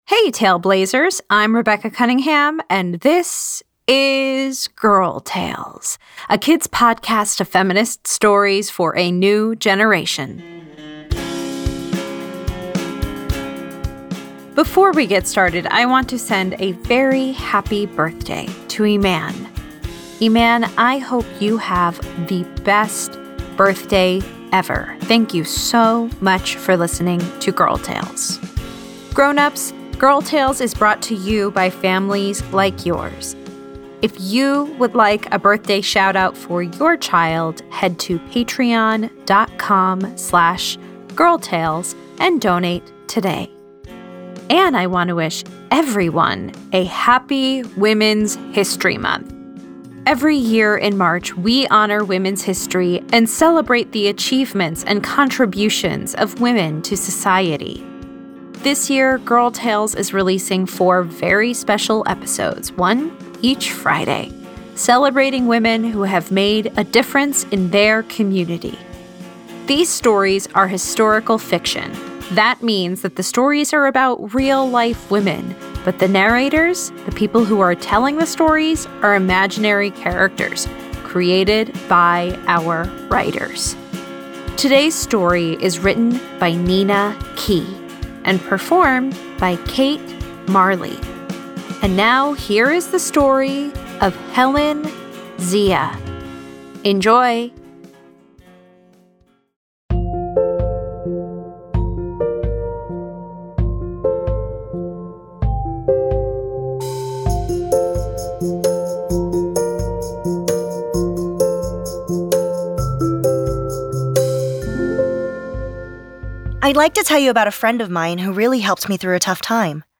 A historical fiction story about advocating for yourself and for the people in your community even in the face of prejudice and racism.